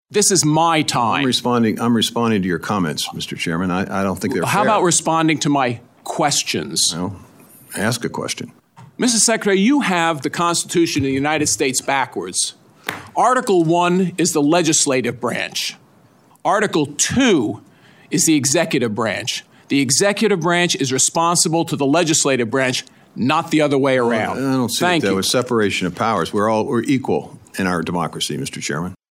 Partisan tensions boiled over at a recent House Ag Appropriations hearing as republicans battled USDA over the President’s proposed budget and regulations.